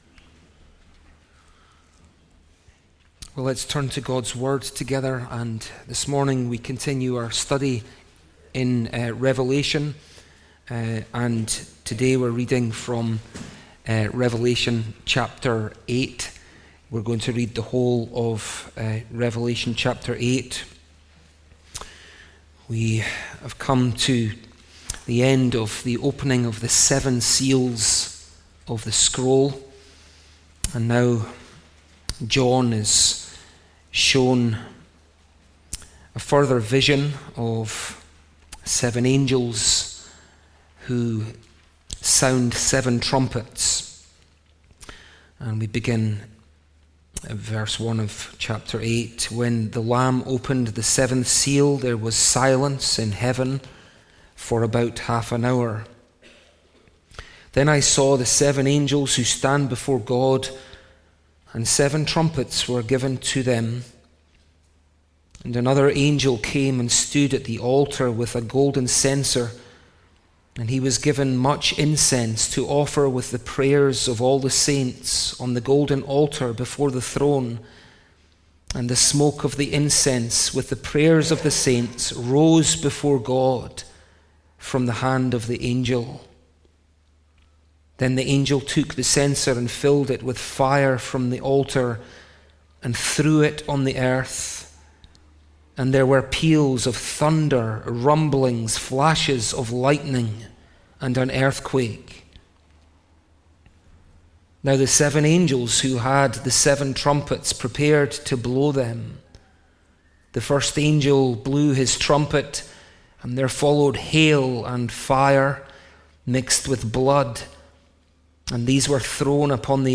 Judgements before the end | SermonAudio Broadcaster is Live View the Live Stream Share this sermon Disabled by adblocker Copy URL Copied!